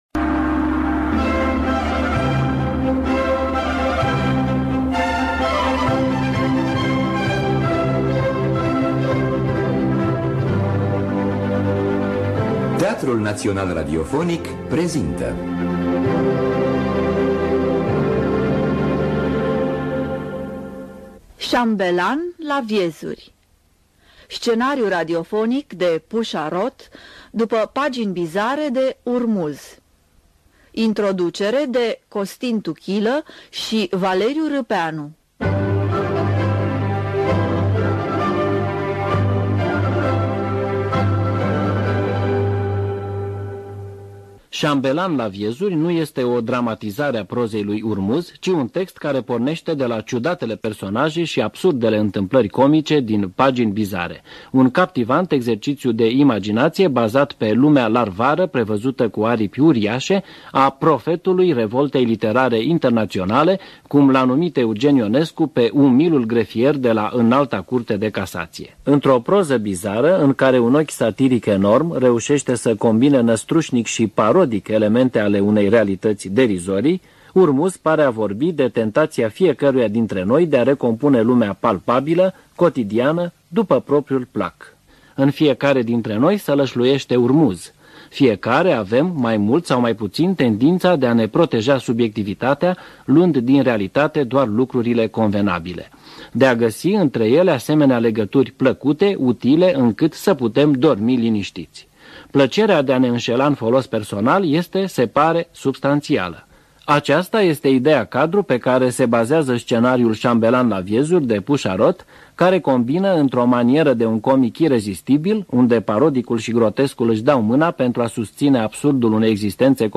Muzică originală
voce
fagot
percuţie